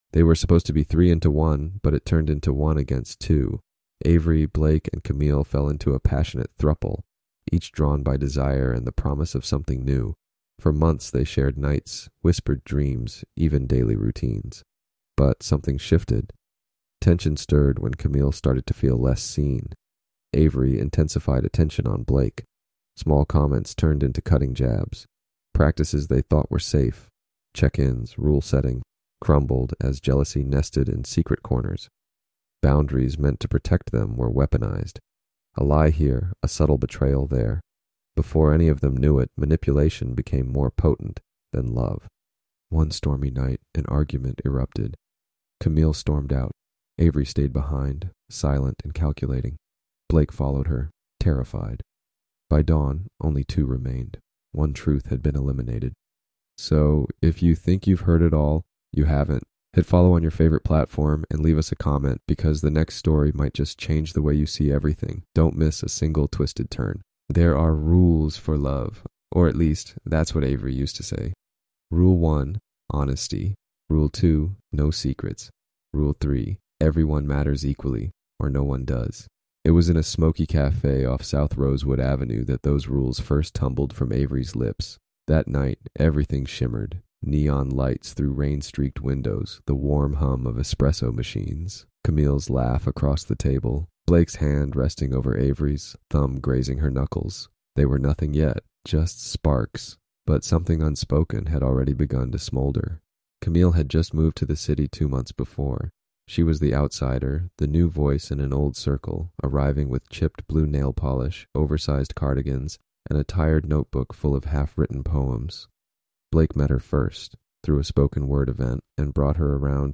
This seven-part audio drama unpacks the terrifying tactics of emotional coercion, toxic control, and the silent violence of narcissistic power games.